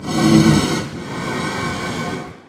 breathe1.mp3